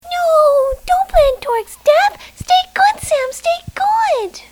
I've also done Kiki sound bites (those of my friends who've read the strip --still trying to recruit more--